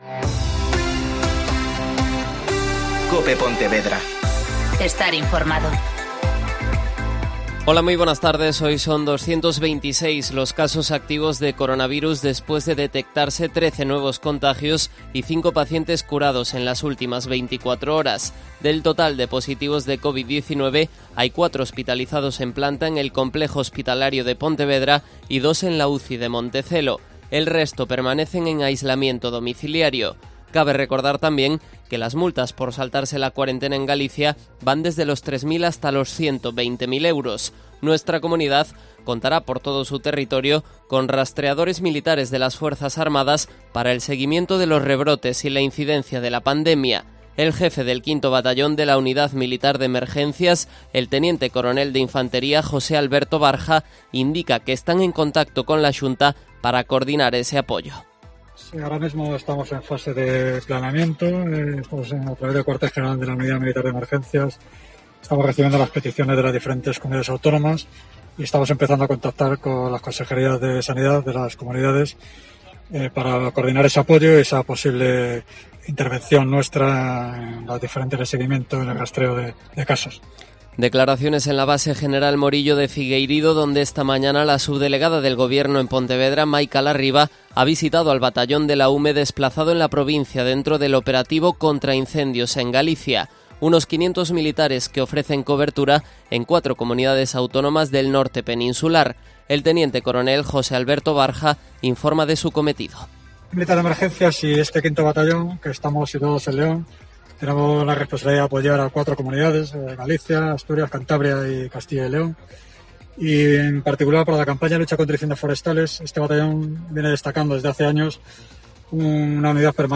Mediodía COPE Pontevedra (Informativo 14:20h)
Amparo González. Directora Xeral de Familia.